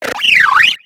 Grito de Unfezant.ogg
Grito_de_Unfezant.ogg